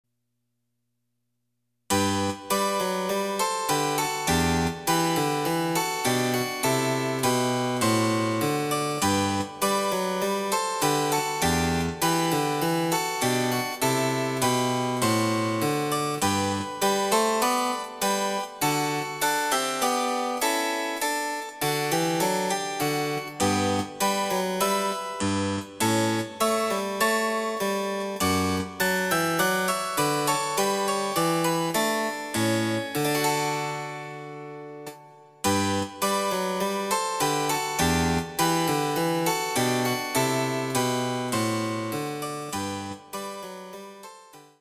★フルートの名曲をチェンバロ伴奏つきで演奏できる、「チェンバロ伴奏ＣＤつき楽譜」です。
試聴ファイル（伴奏）
※フルート奏者による演奏例は収録されていません。